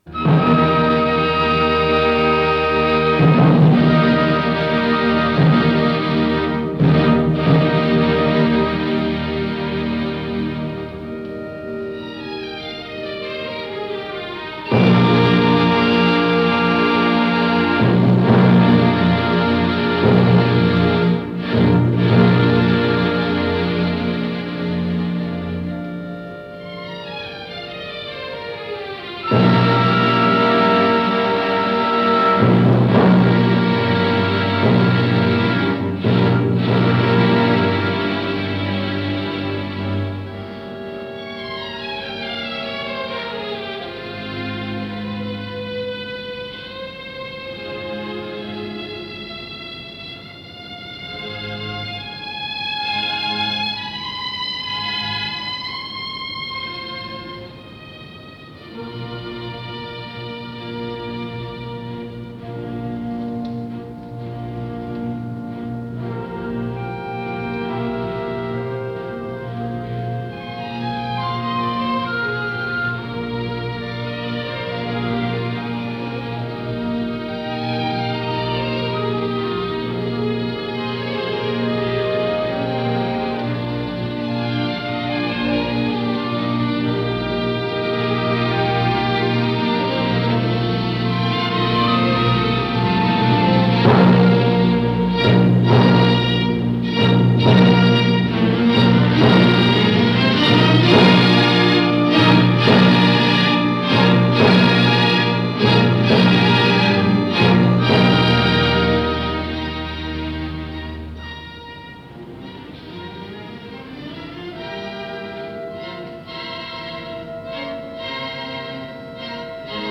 с профессиональной магнитной ленты
К-543, ми бемоль мажор
ИсполнителиОркестр Берлинской филармонии
Дирижёр - Вильгельм Фуртвенглер
ВариантДубль моно